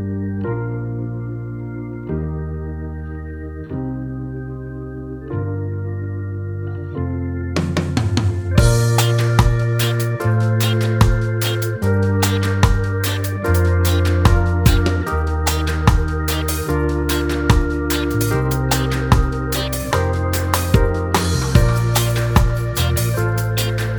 Minus Main Guitar Pop (2010s) 4:24 Buy £1.50